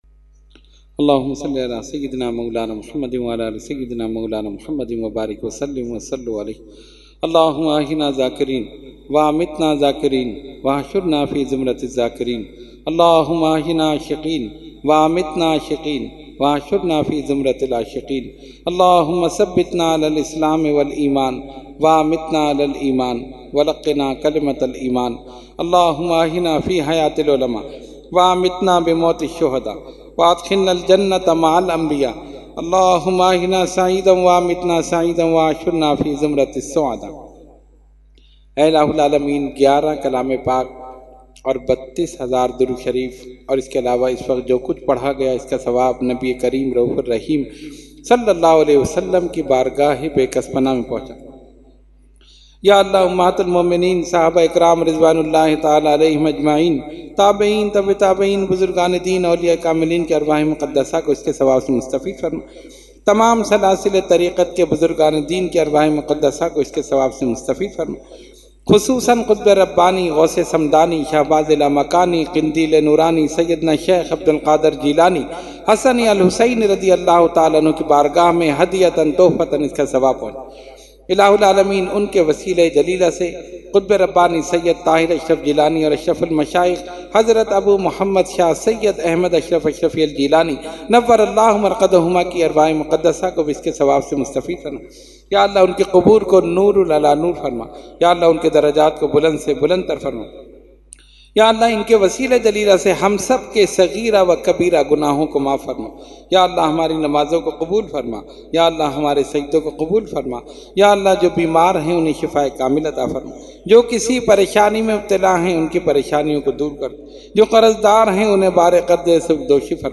Category : Dua | Language : UrduEvent : 11veen Shareef 2019